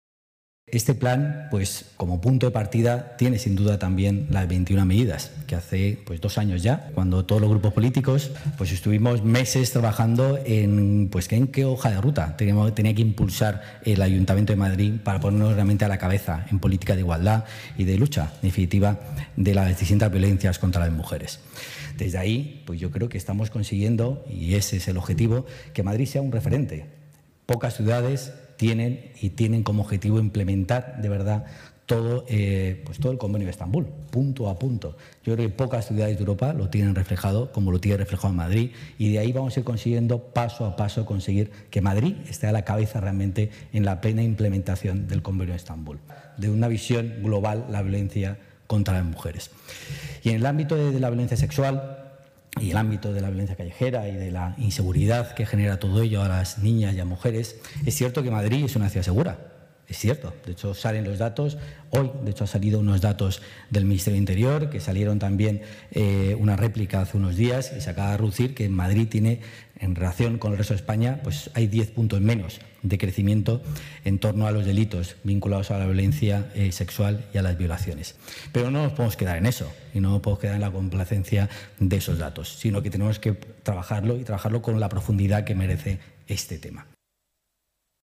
Presentado hoy por el delegado de Familias, Igualdad y Bienestar Social, Pepe Aniorte
Nueva ventana:Pepe Aniorte en la clausura de la presentación del Plan Madrid Ciudad Segura